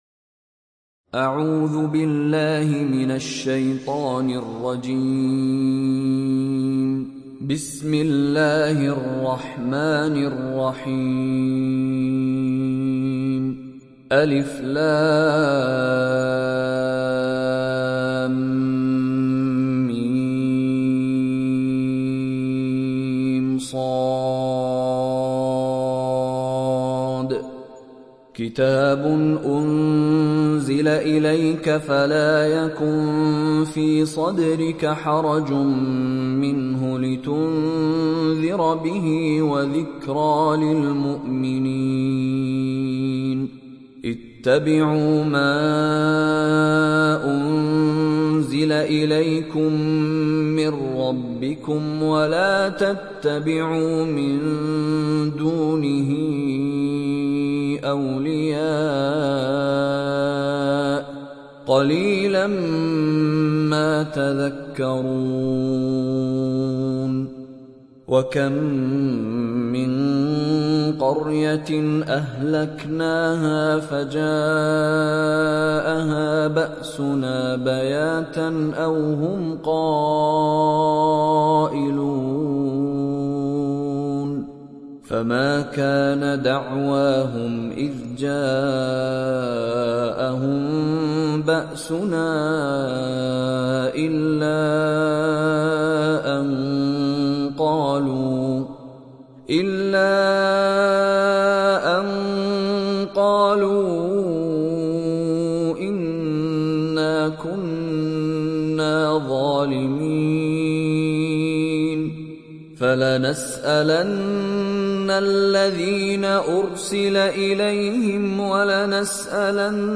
Surat Al Araf mp3 Download Mishary Rashid Alafasy (Riwayat Hafs)